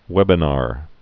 (wĕbə-när)